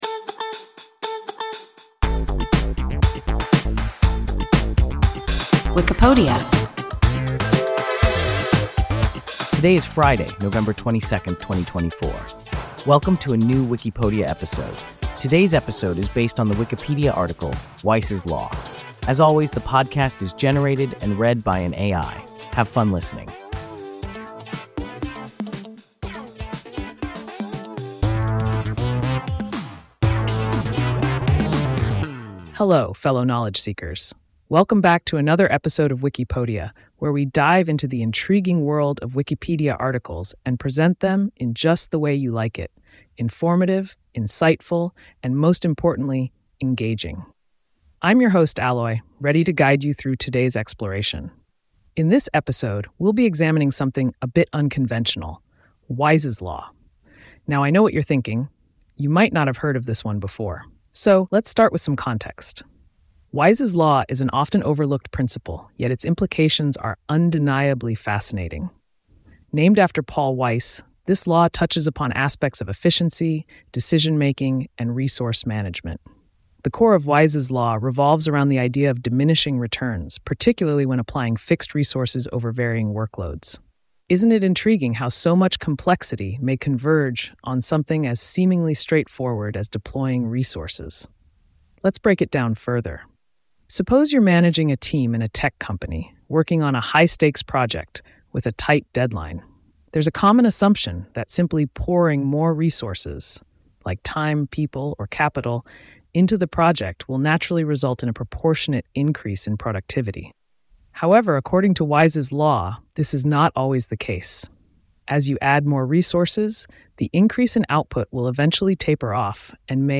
Weise’s law – WIKIPODIA – ein KI Podcast